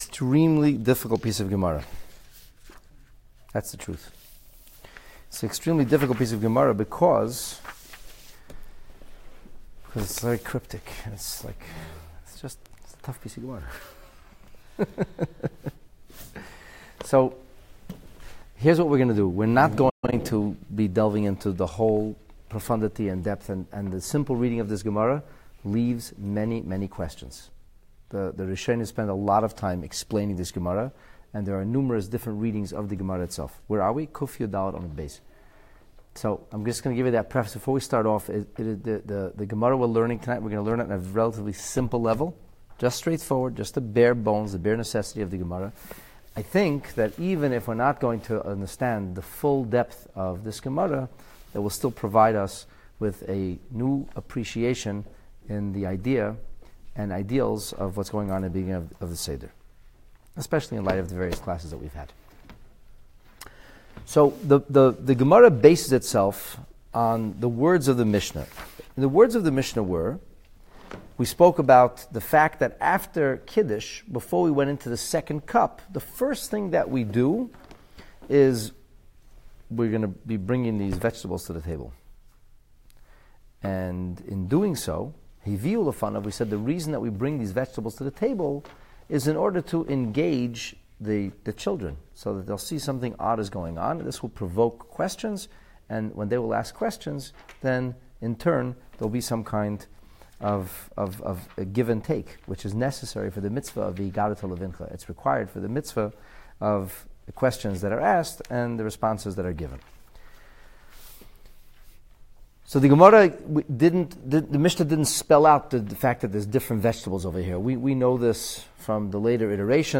Talmud Tisch: Intermediate Talmud Masechet Pesachim The Talmud on the Pesach Seder Lesson 8 In its initial analysis of the Mishnah, a great sage presumed to have uncovered the answer to a question that had long baffled the sages: must a mitzvah be performed intentionally, or can meaning be attributed to mindless actions? The Talmud engages in rigorous, its unique signature give-and-take, to meticulously analyze the supposition and frame it in the precision-driven syntax our sages employed.